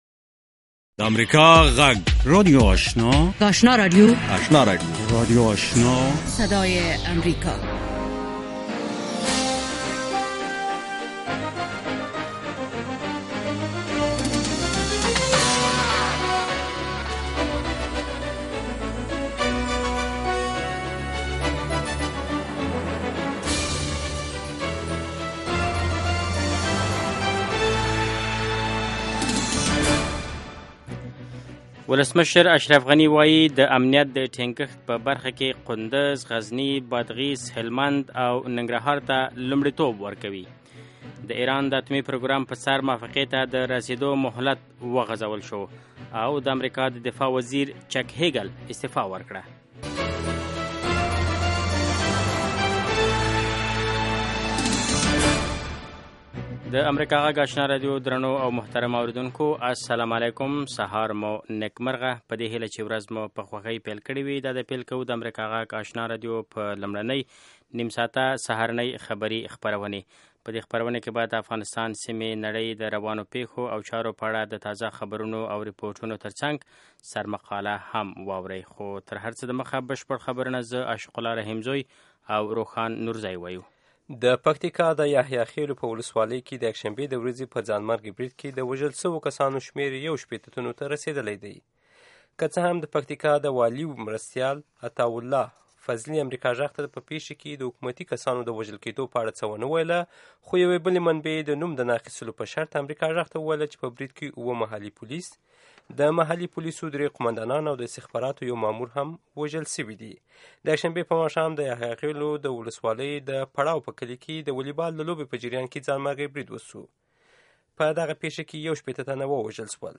لومړنۍ سهارنۍ خبري خپرونه